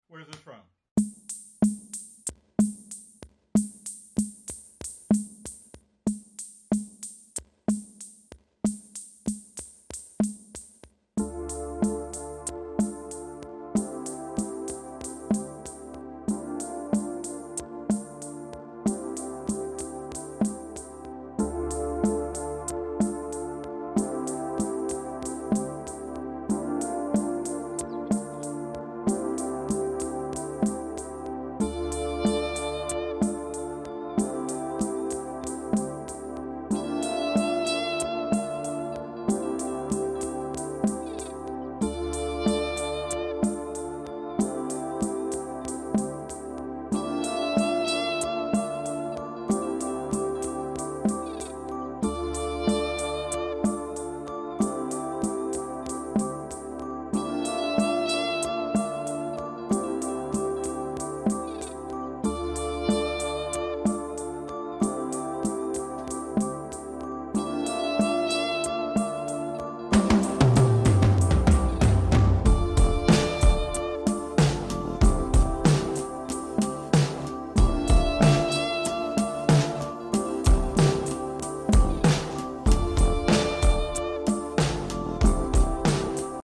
Maybe The Most Famous Drum Sound Effects Free Download